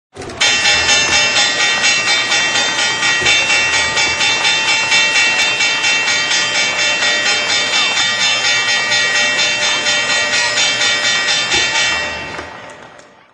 notifcation.mp3